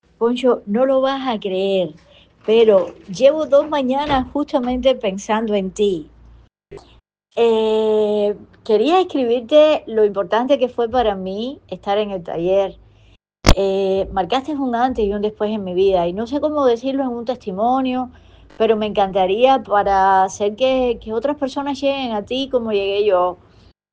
Testimoniales
Algunas de los egresadas de talleres anteriores quieren compartir contigo lo que el taller representó para ellas, para sus vidas.
testimonial.mp3